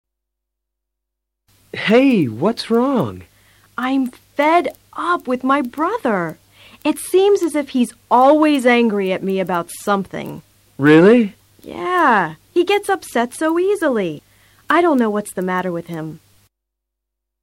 A continuación escucharás a cuatro parejas alabando o criticando a otras personas.